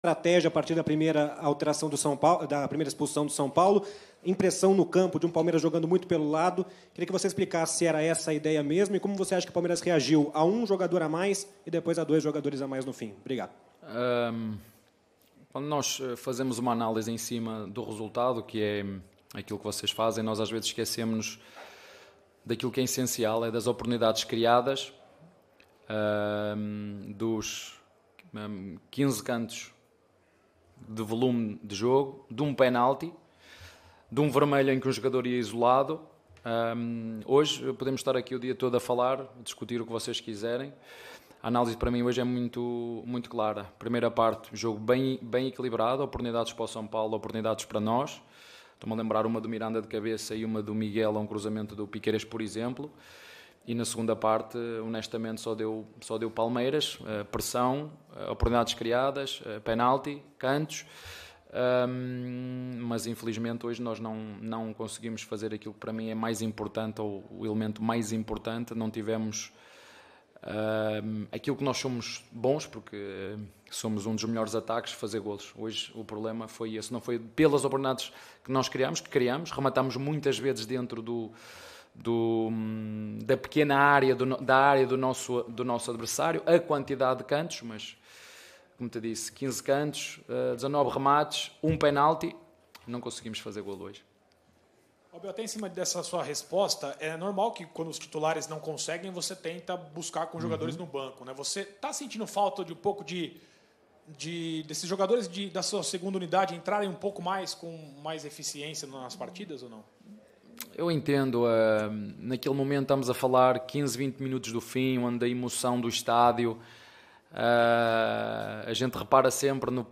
COLETIVA-ABEL-FERREIRA-_-PALMEIRAS-X-SAO-PAULO_-BRASILEIRO-2022.mp3